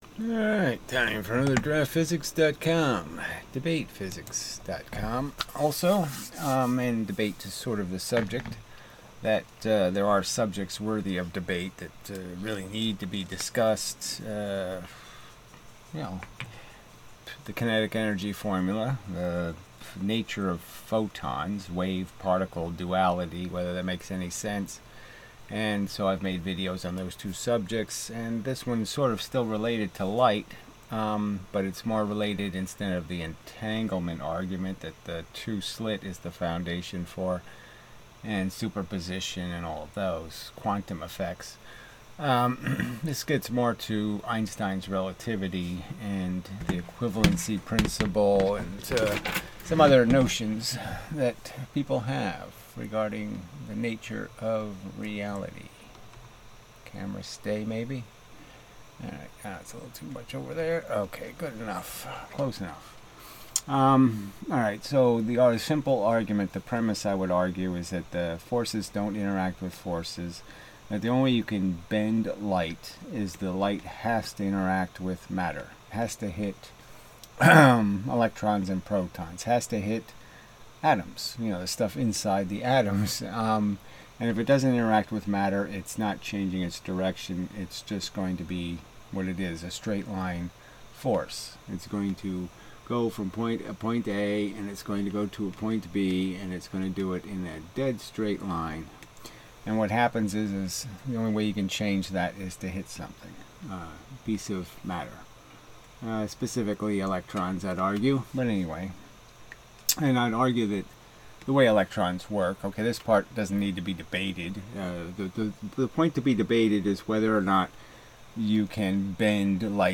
debate subject: Bent Light [12/19/22]